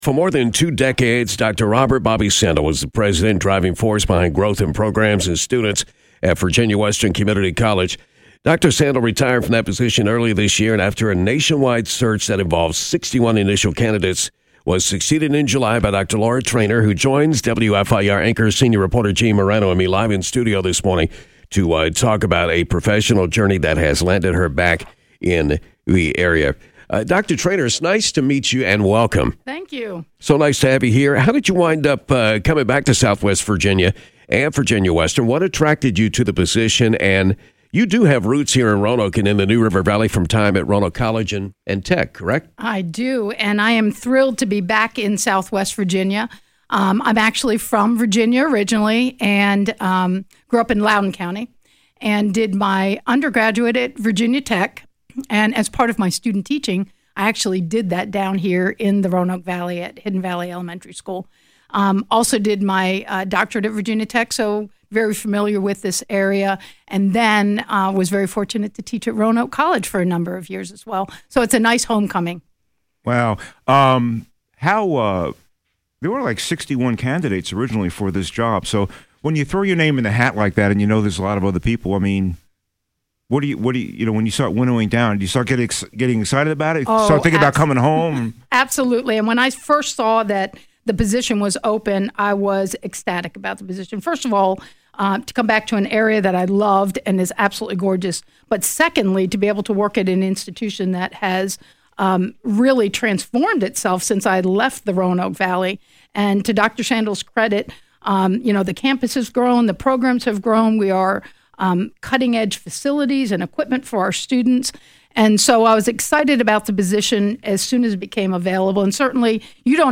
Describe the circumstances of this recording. live in studio this morning